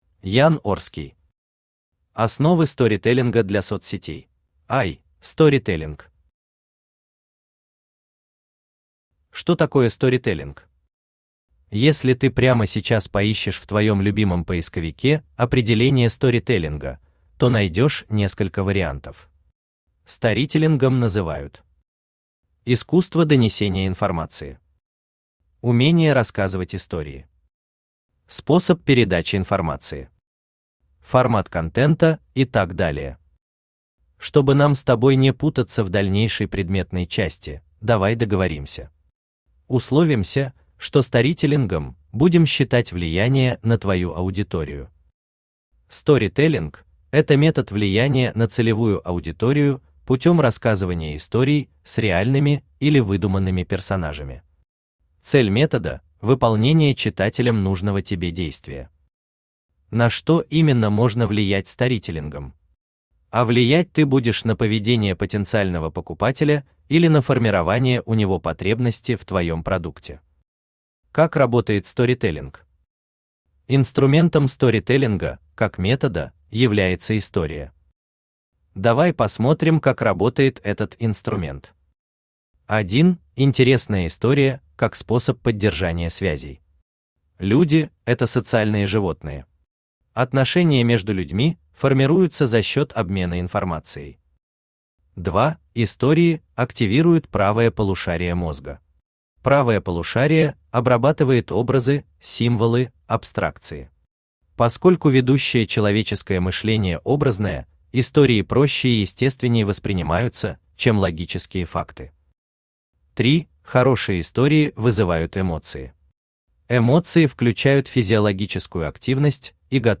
Аудиокнига Основы сторителлинга для соцсетей | Библиотека аудиокниг
Aудиокнига Основы сторителлинга для соцсетей Автор Ян Орский Читает аудиокнигу Авточтец ЛитРес.